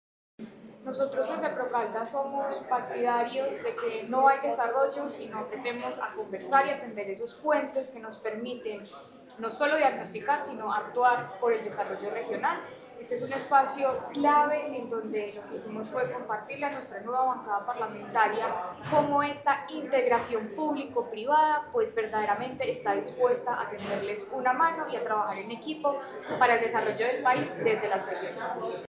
Con un mensaje de articulación y trabajo conjunto, la Secretaría de Planeación de la Gobernación de Caldas lideró el encuentro estratégico ‘Caldas Marca la Ruta: una visión de territorio para los próximos años’, un espacio que reunió a la institucionalidad, el sector privado, la academia y la bancada parlamentaria electa para construir una agenda común que proyecte al departamento en el escenario nacional.